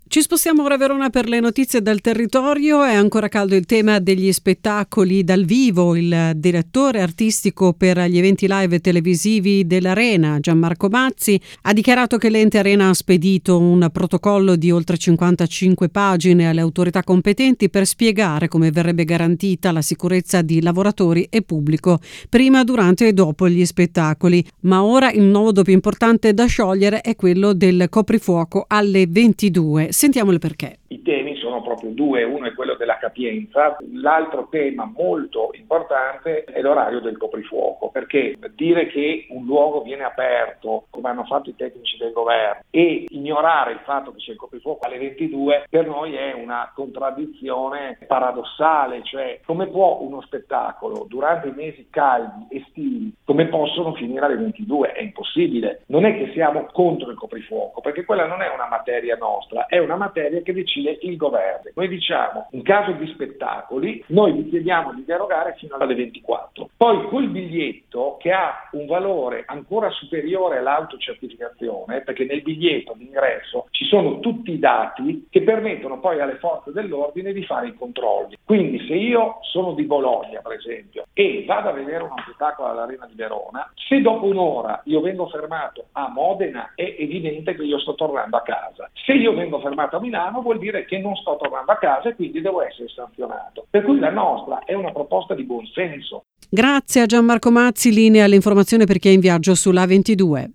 12 maggio: intervista a Gianmarco Mazzi direttore artistico per gli eventi live e televisivi dell’Ente Arena di Verona, sulla difficoltà di organizzare spettacoli con il coprifuoco in vigore: